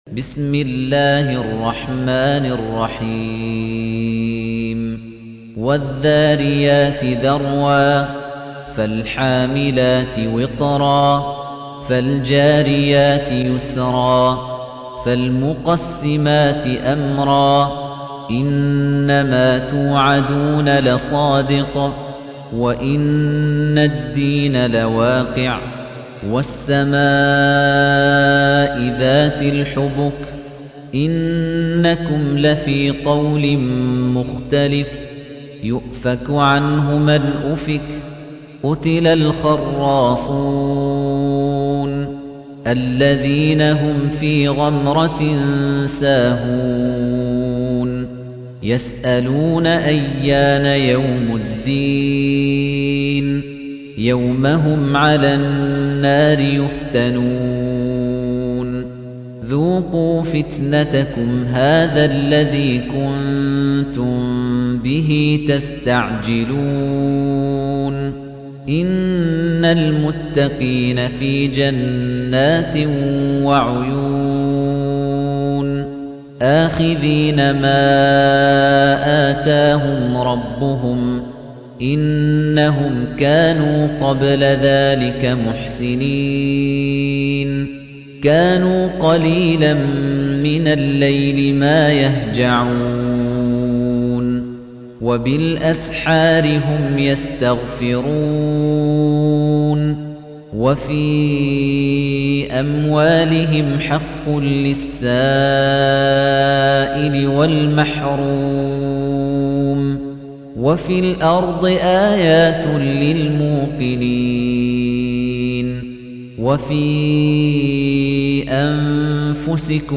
تحميل : 51. سورة الذاريات / القارئ خليفة الطنيجي / القرآن الكريم / موقع يا حسين